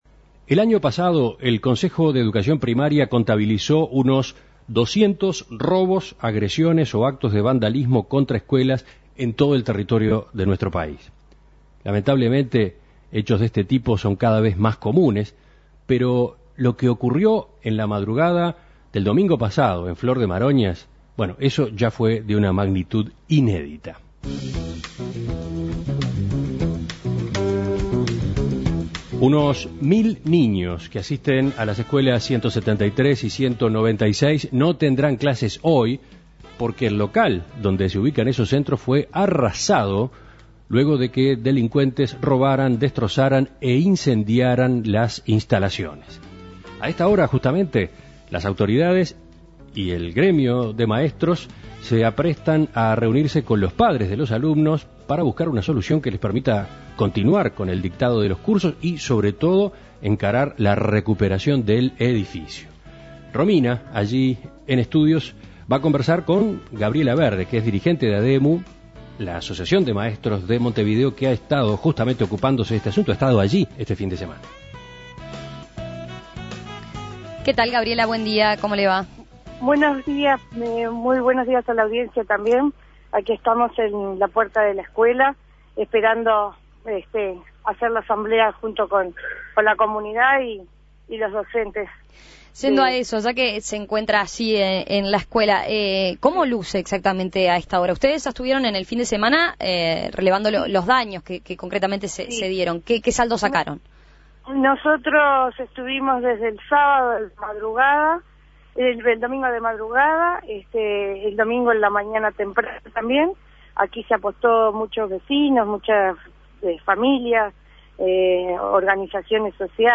Para profundizar sobre este acontecimiento y las medidas que se van a tomar, En Perspectiva entrevistó